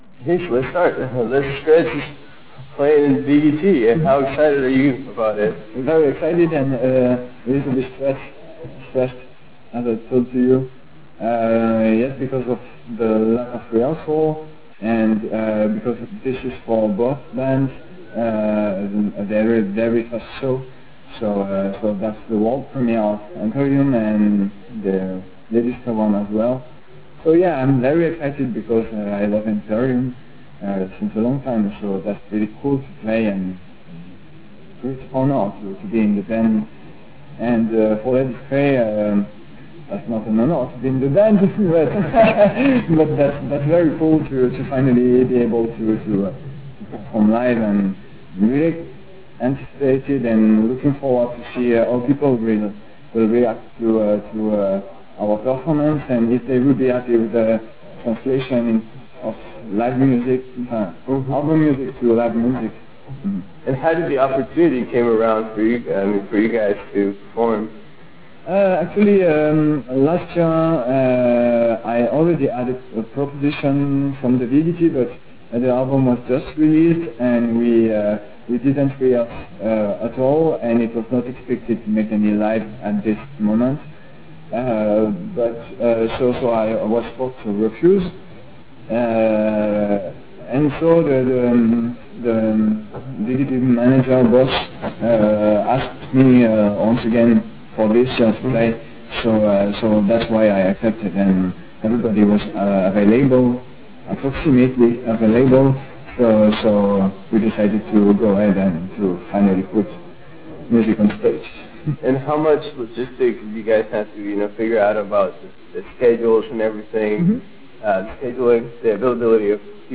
We also discussed his appearance with Empyrium (first live show...ever) also at WGT 2011, and if you are very attentive you can listen to them rehearse in the background of the interview. Lastly, we discuss the band's upcoming release and what can fans expect from it.
Interview is around 16 minutes long